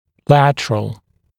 [‘lætərəl][‘лэтэрэл]боковой; поперечный; направленный в сторону